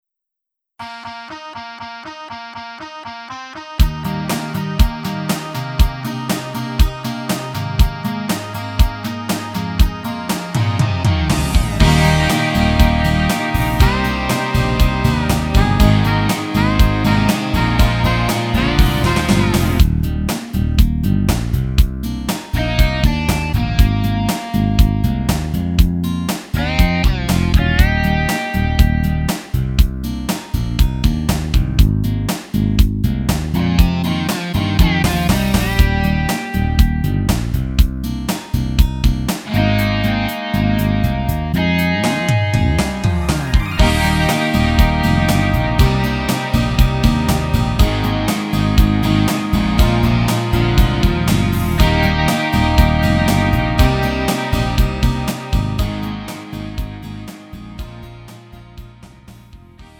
음정 원키 2:45
장르 구분 Lite MR